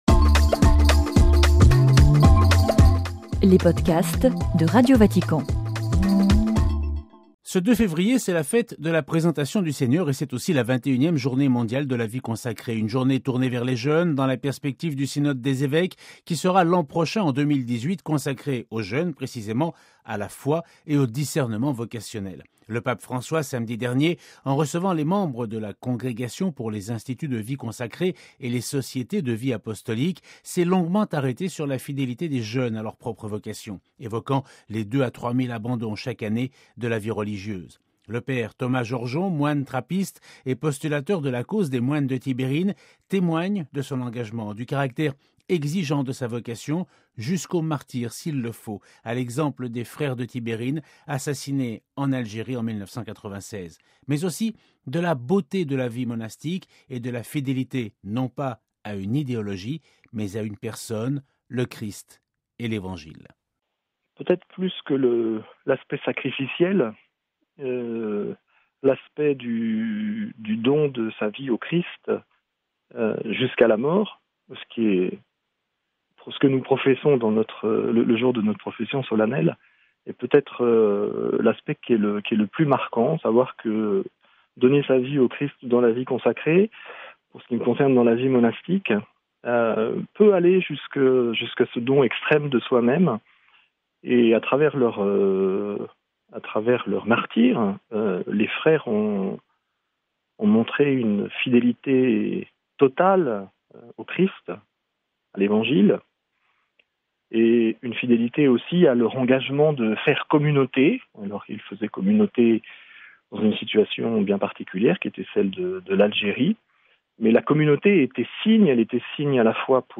(RV) Entretien - Ce 2 février 2017 marque la Fête de la Présentation du Seigneur, ainsi que la Journée mondiale de la Vie consacrée : une journée tournée vers les jeunes, dans la perspective du synode des Évêques qui sera l’an prochain consacré aux jeunes, à la foi, et au discernement vocationnel.